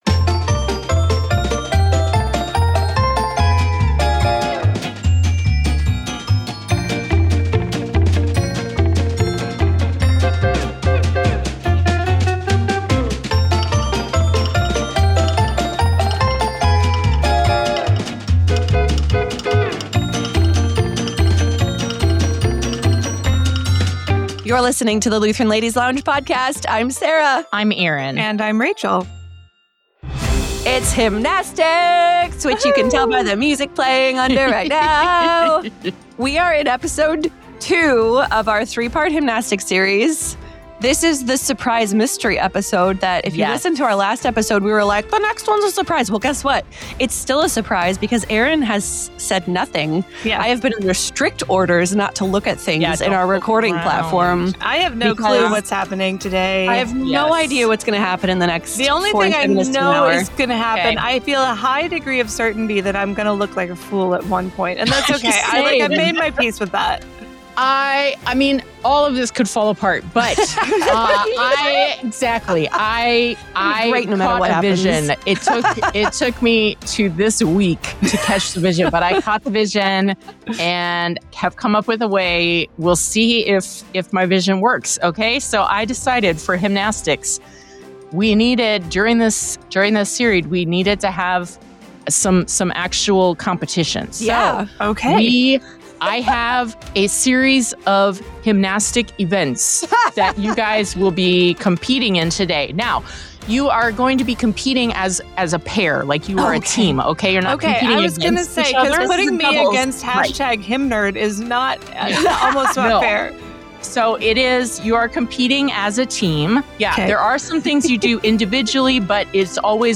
Over three — make that four — consecutive episodes, they’ll laugh, they’ll cry, they’ll sweat (sometimes literally), and above all, they’ll sing as they celebrate some of the greatest hymns and hymnwriters past, present, and even yet to come.